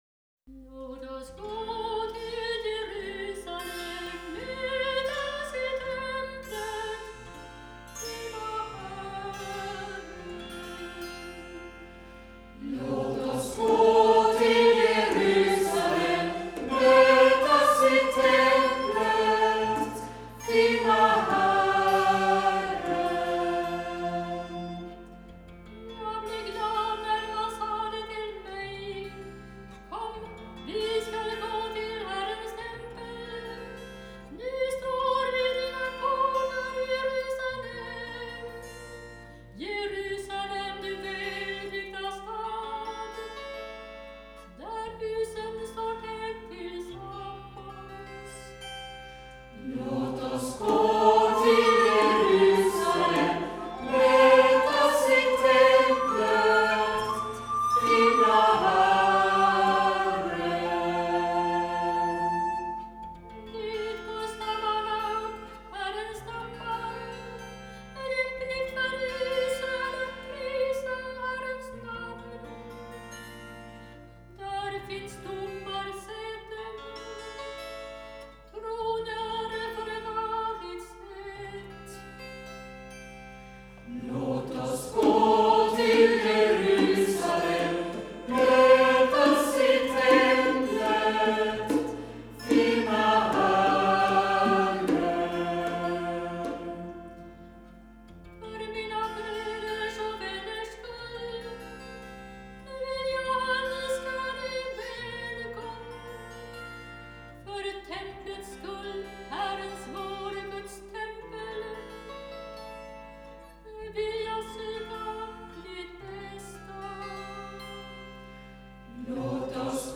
systrar tillsammans med vänner